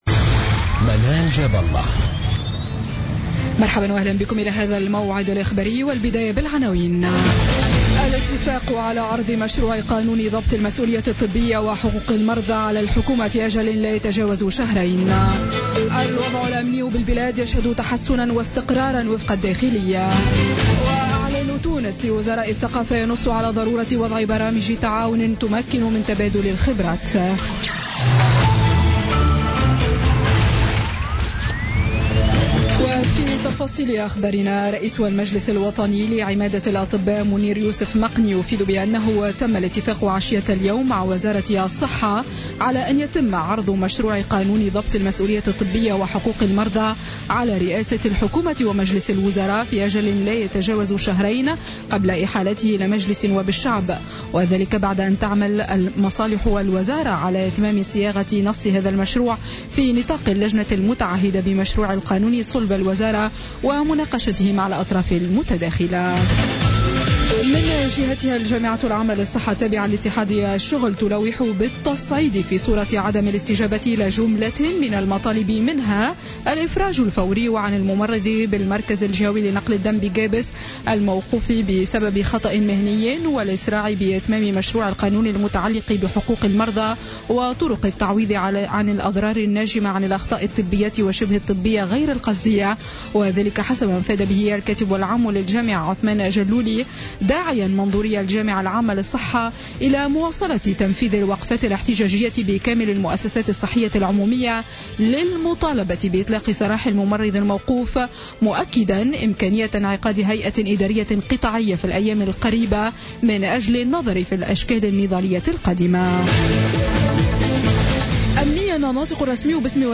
نشرة أخبار السابعة مساء ليوم الجمعة 10 فيفري 2017